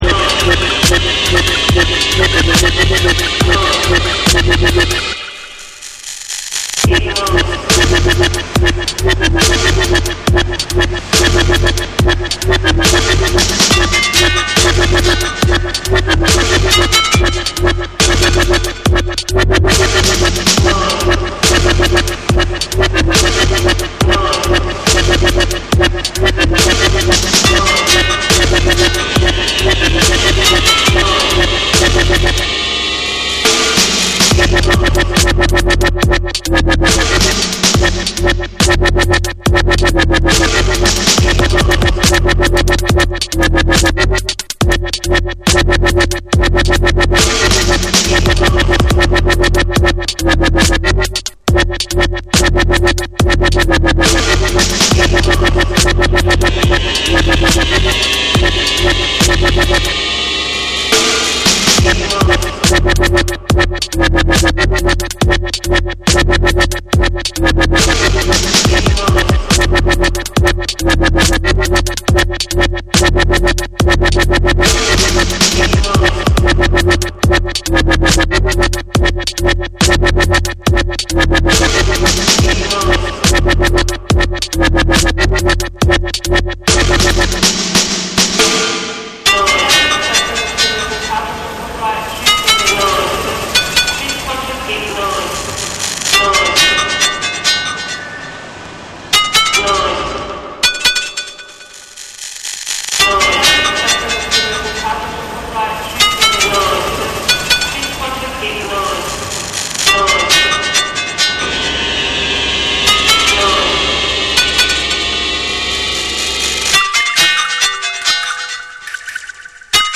心の低いグルーヴとエッジの効いたサウンドデザインが際立つ
BREAKBEATS / DUBSTEP